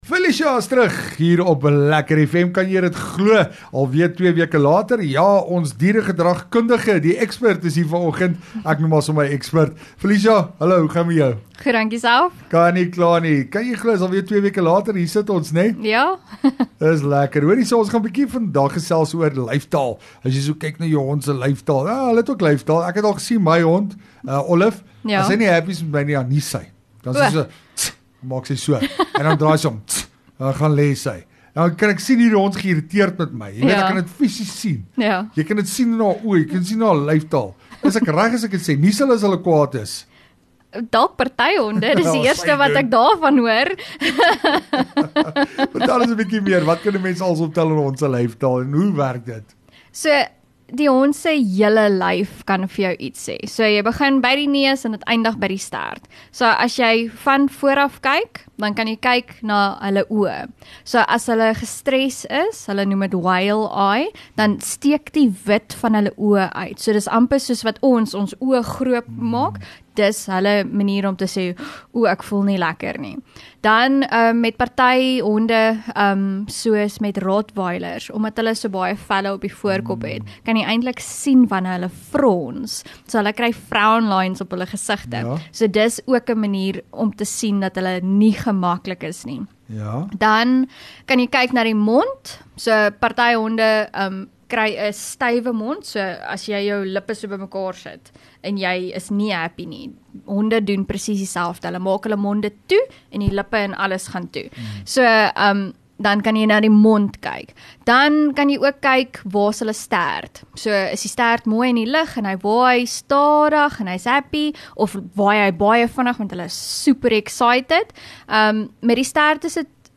Dieregedragkundige